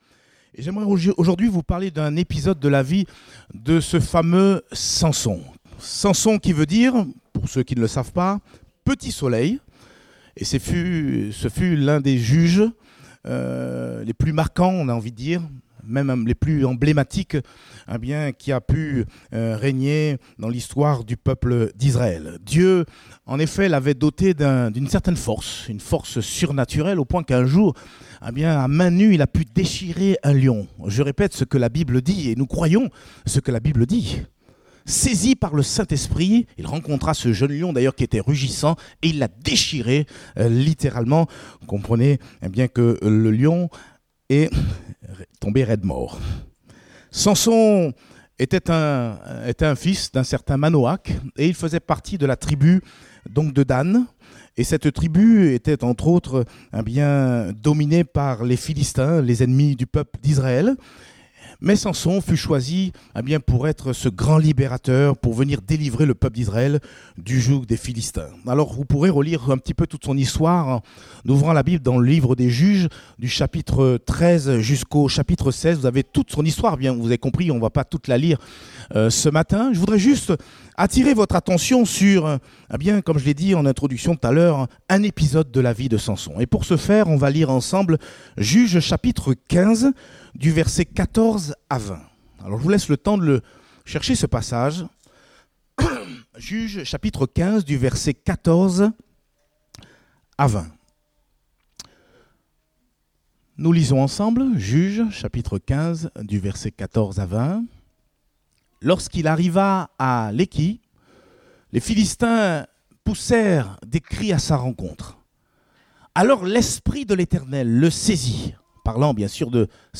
Date : 25 août 2019 (Culte Dominical)